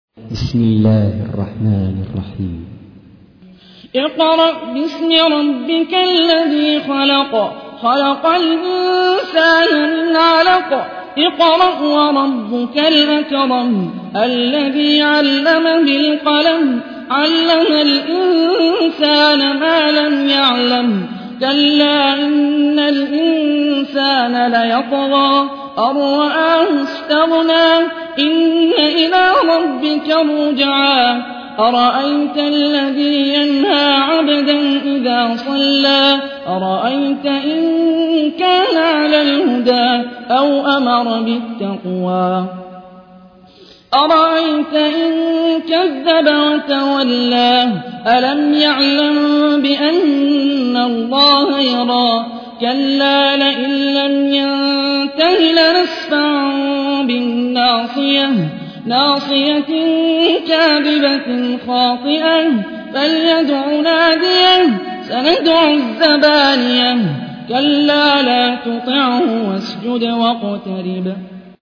تحميل : 96. سورة العلق / القارئ هاني الرفاعي / القرآن الكريم / موقع يا حسين